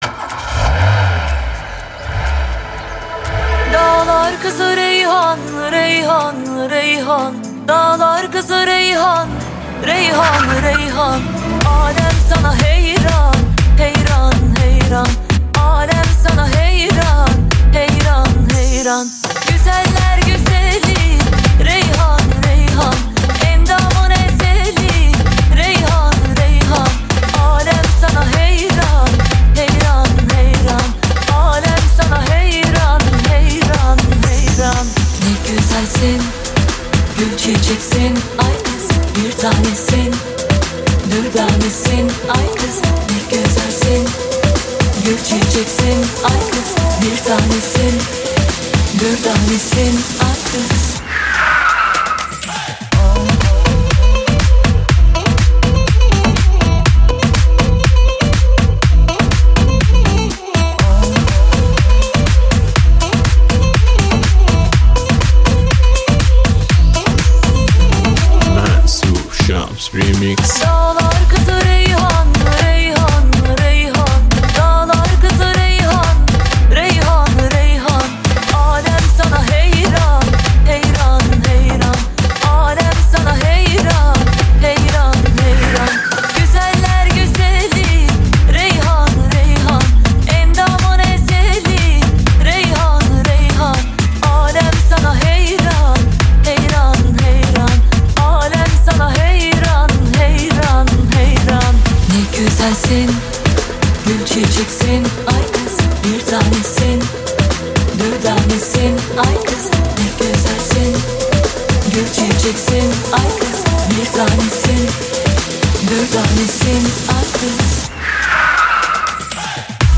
ریمیکس شاد ترکی